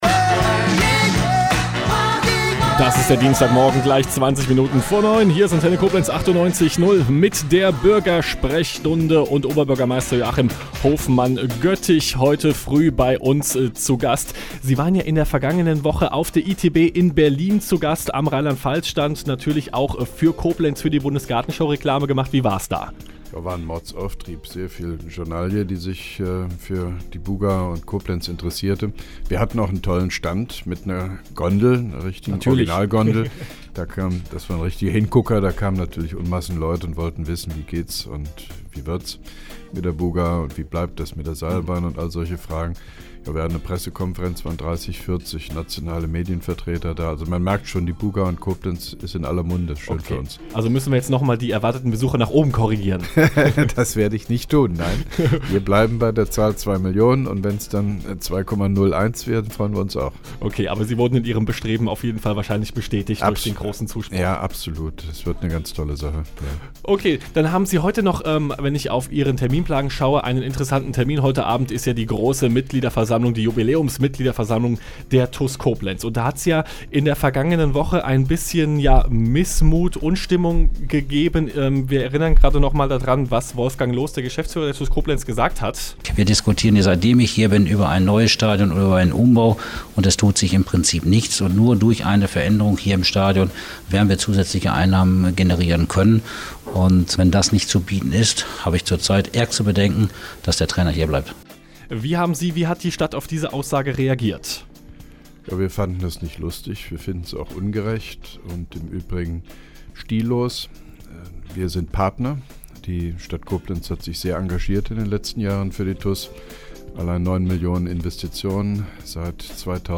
(1) Koblenzer Radio-Bürgersprechstunde mit OB Hofmann-Göttig 15.03.2011